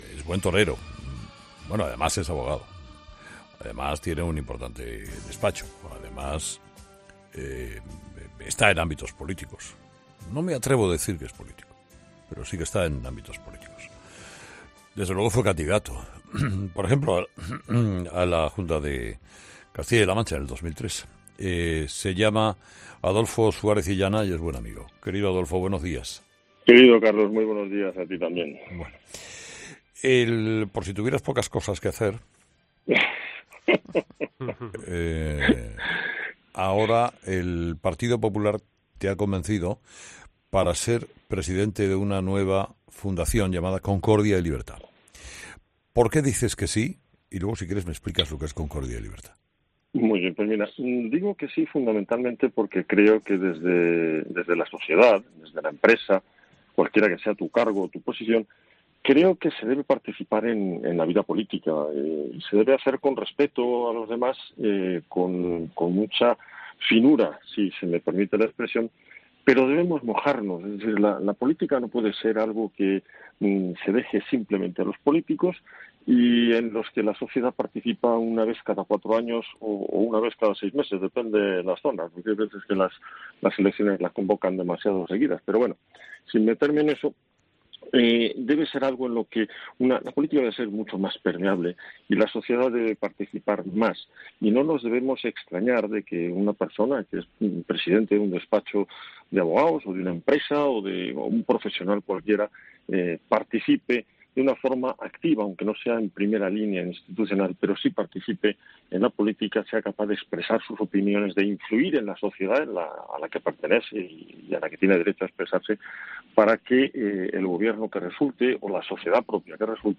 Entrevista a Suárez Illana, presidente de la Fundación Concordia y Libertad